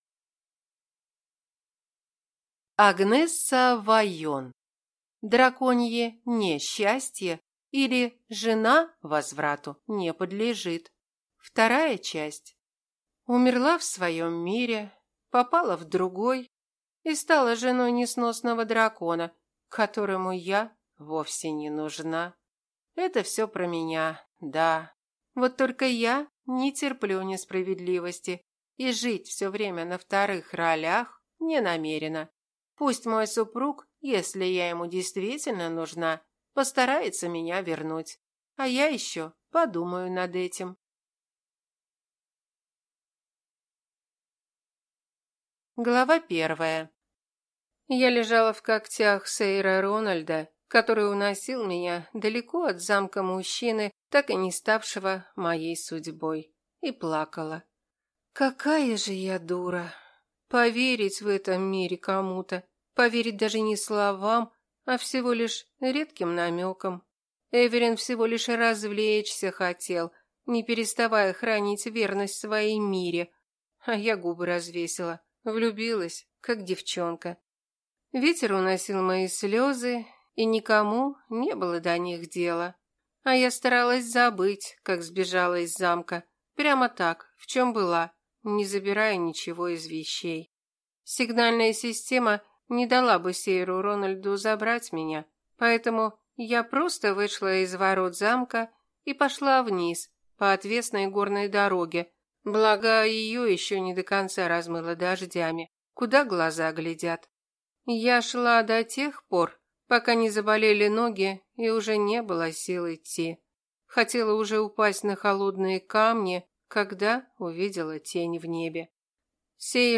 Аудиокнига Драконье (не)счастье, или Жена возврату не подлежит | Библиотека аудиокниг